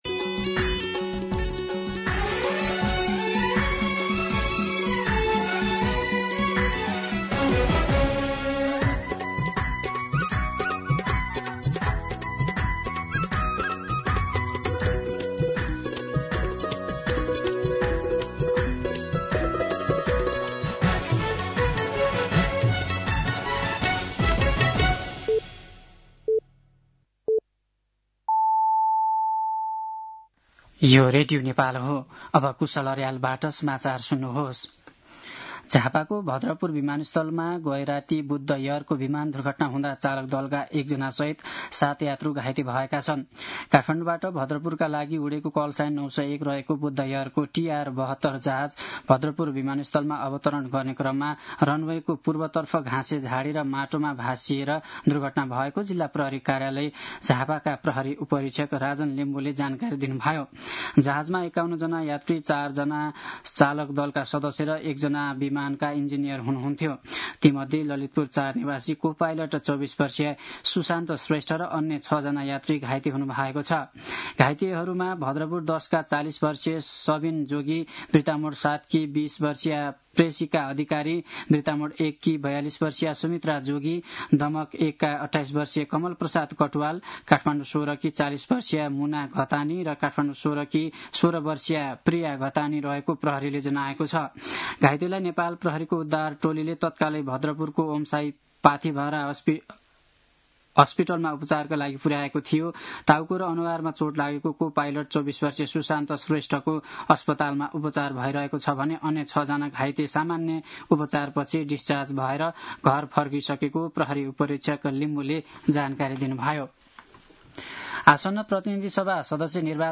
मध्यान्ह १२ बजेको नेपाली समाचार : १९ पुष , २०८२